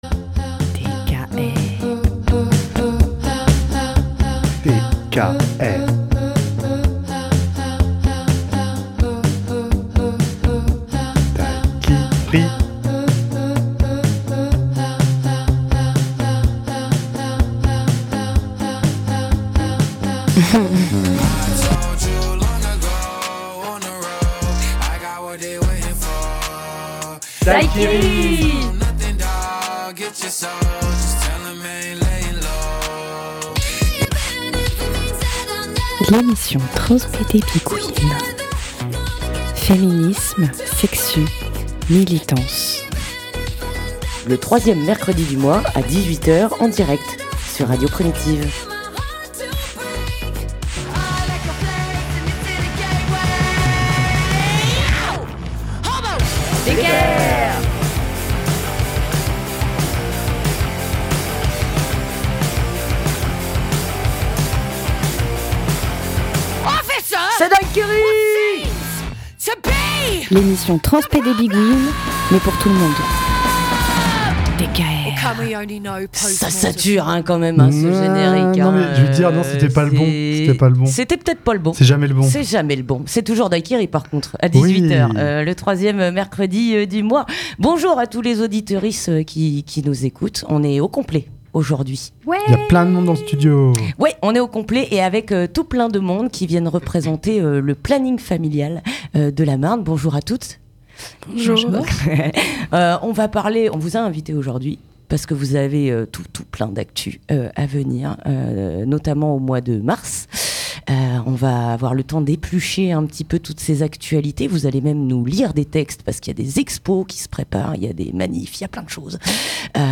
On reçoit les militantes du Planning Familial de la Marne pour parler de l'exposition qui tournera pour leur anniversaire.